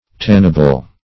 Tannable \Tan"na*ble\, a. That may be tanned.